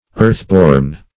\earth"-born\([~e]rth"b[^o]rn`), a.